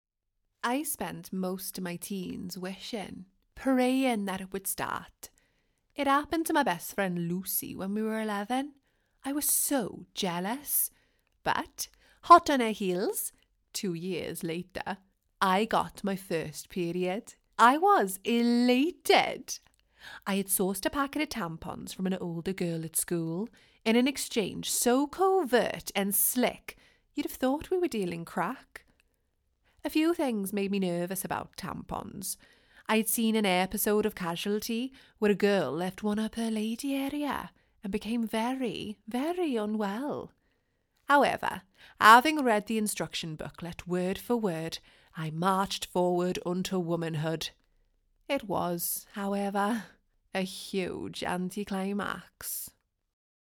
Cheerful, Neutral, Engaging, Current, Velvety, Expressive
Welsh-Accent.mp3